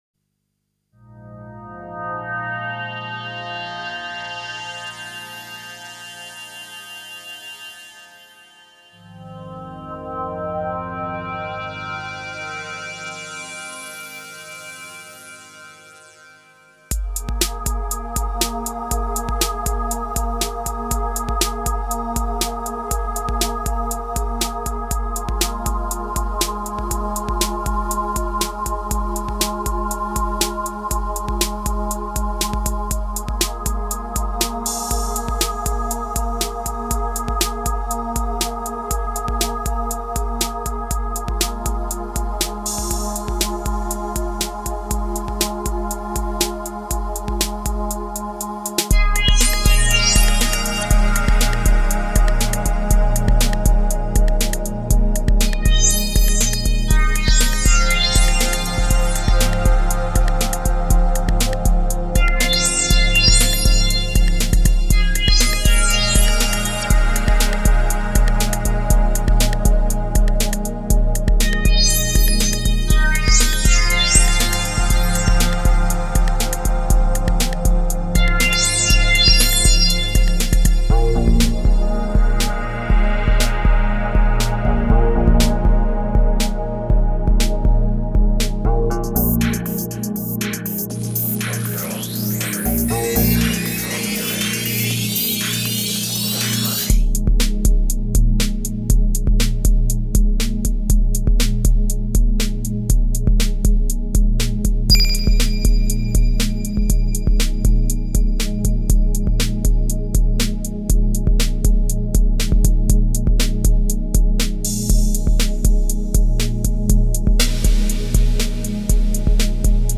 Chillout, LA style.